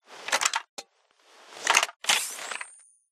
Wpn_riflelaser_reloadinout.ogg